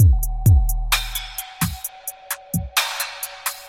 新大鼓
描述：这是一种非常独特的鼓的类型
标签： 65 bpm Weird Loops Drum Loops 636.23 KB wav Key : Unknown
声道立体声